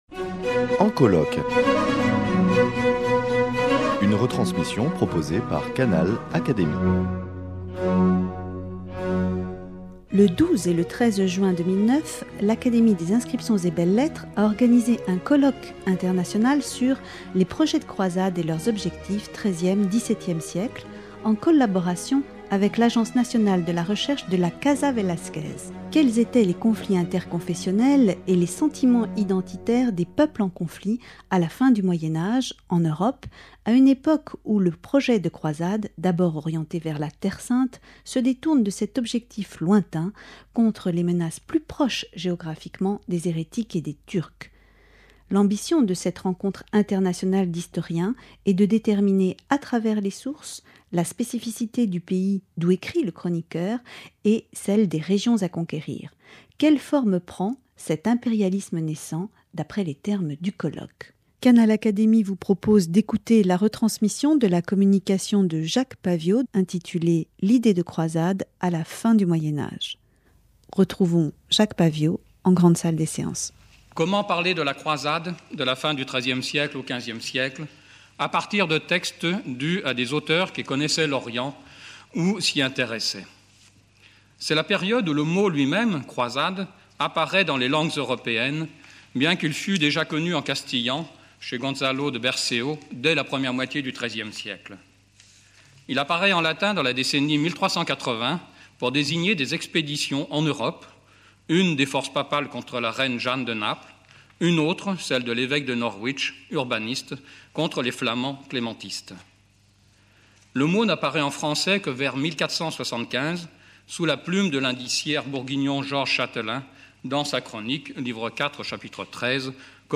En colloque